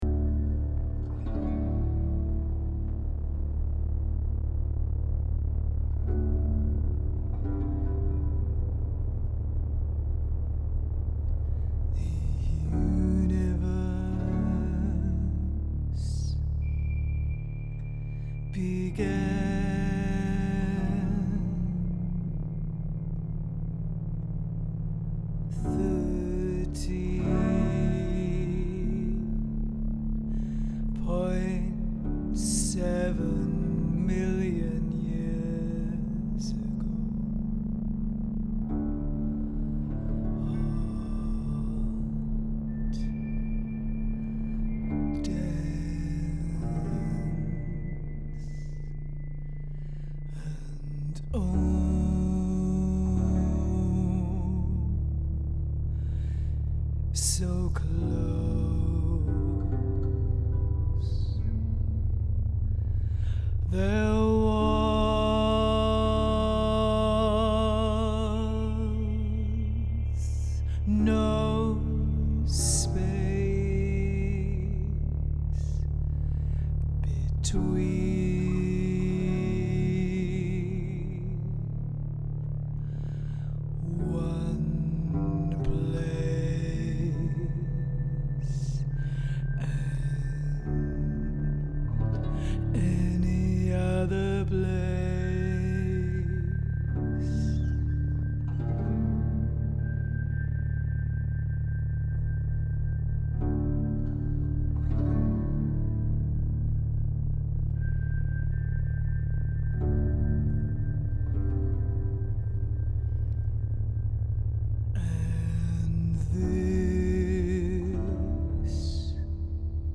This cabaret performance was inspired by a research trip to the Swedish and Finnish Arctic and visits to Abisko Sky Station, The Swedish Institute for Space Physics and the University of Lapland. The story that unfolds at the Polestar Club begins before the universe came into being.